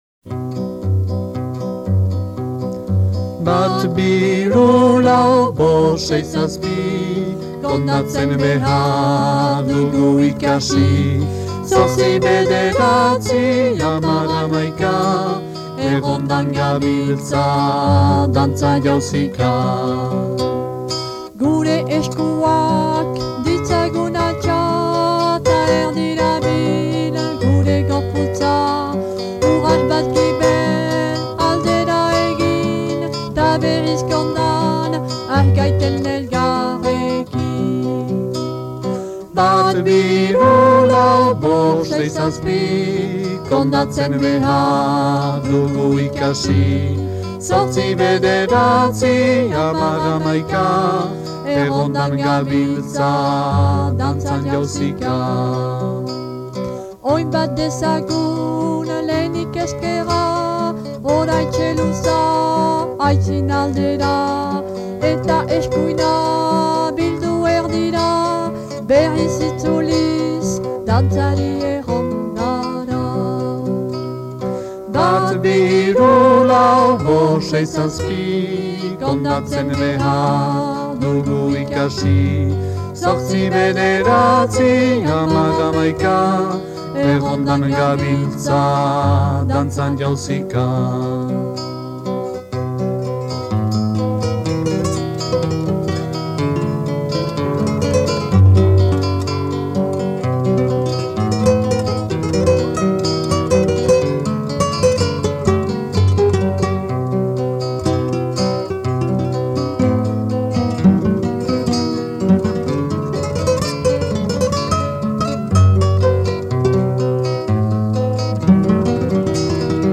This series of Basque songs for kids was compiled by Basque newspaper EGUNKARIA.